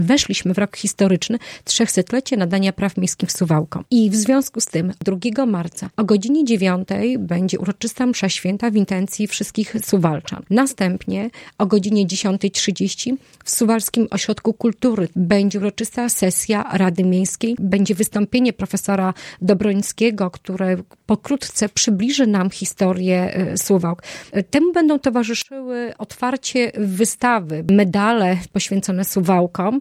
Szczegóły przedstawiła w radiu 5 Ewa Sidorek – zastępca prezydenta Suwałk.